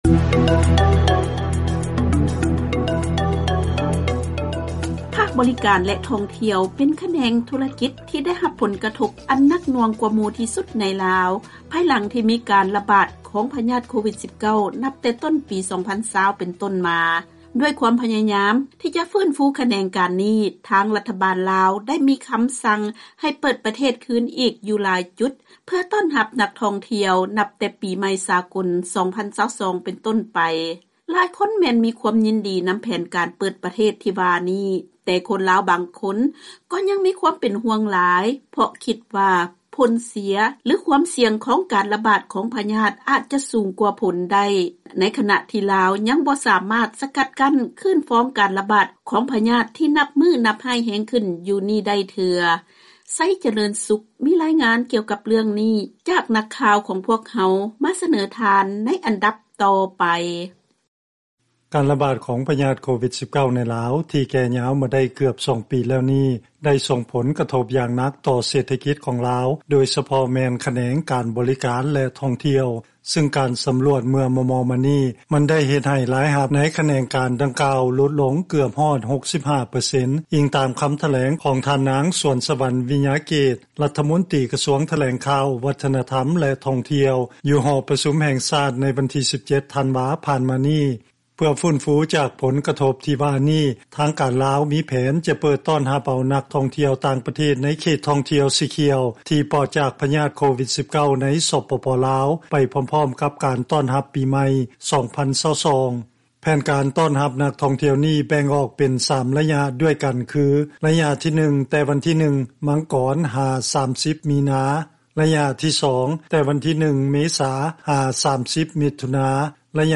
ເຊີນຟັງລາຍງານ ຄົນລາວບາງສ່ວນ ສະແດງຄວາມຄິດເຫັນ ຕໍ່ແຜນການເປີດປະເທດຄືນໃໝ່ ເພື່ອຕ້ອນຮັບນັກທ່ອງທ່ຽວ ຂອງລັດຖະບານ
ແຜນການທີ່ວ່ານີ້ເຮັດໃຫ້ປະຊາຊົນລາວ ໂດຍສະເພາະຜູ້ທີ່ເຮັດອາຊີບໃນພາກ ບໍລິການແລະທ່ອງທ່ຽວນັ້ນ ມີຄວາມດີໃຈ ດັ່ງທີ່ພະນັກງານໃນບໍລິສັດທ່ອງທ່ຽວ ທ່ານນຶ່ງ ໃນນະຄອນຫລວງວຽງຈັນກ່າວຕໍ່ພວກເຮົາວ່າ: